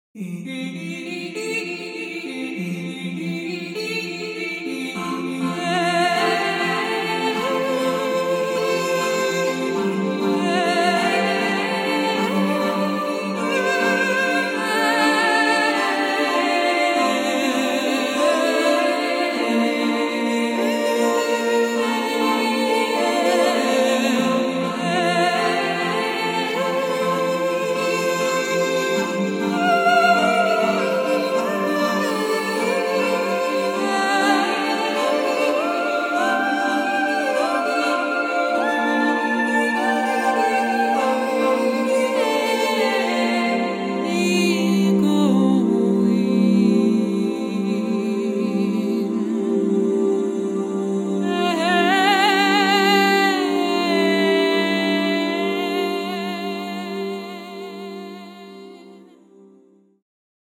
Vocal
modular female solo vocal series
mezzo-soprano voice